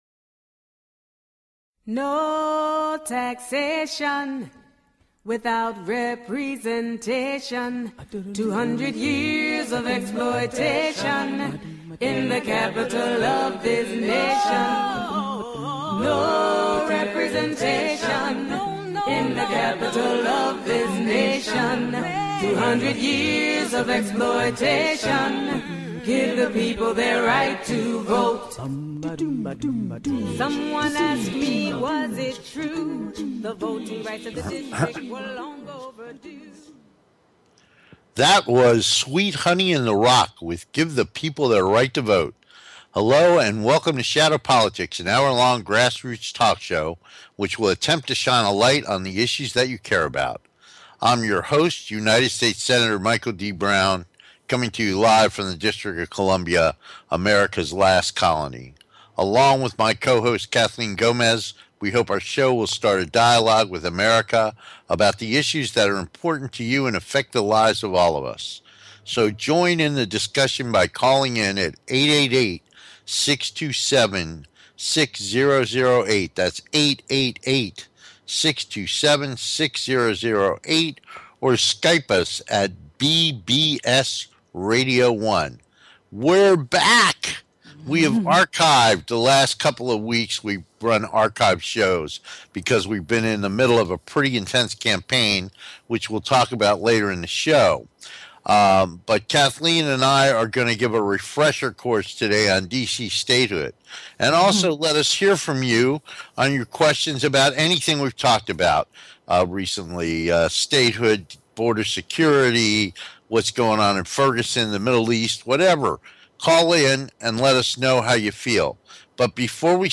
Shadow Politics is a grass roots talk show giving a voice to the voiceless. For more than 200 years the people of the Nation's Capital have ironically been excluded from the national political conversation.
We look forward to having you be part of the discussion so call in and join the conversation.